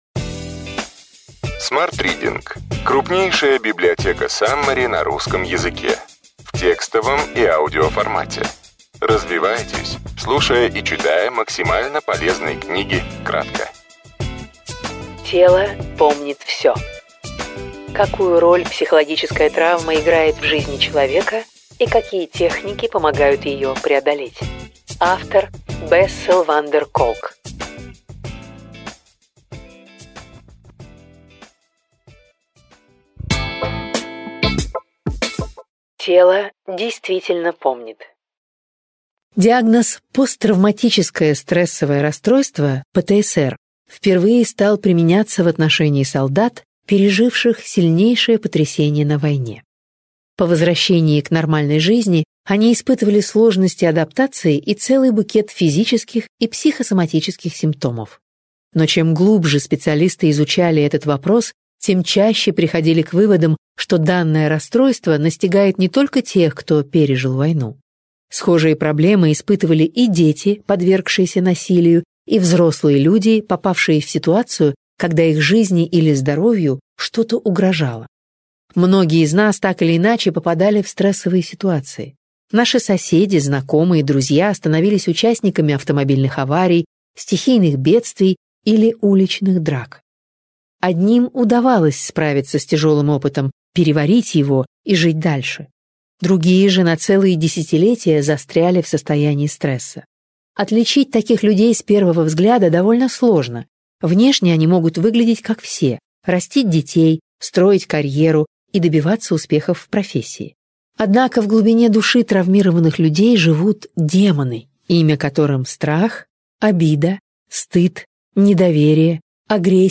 Аудиокнига Ключевые идеи книги: Тело помнит все.